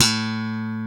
Index of /90_sSampleCDs/Roland - Rhythm Section/BS _E.Bass v_s/BS _P.Bs _ Slap
BS  POPS P0B.wav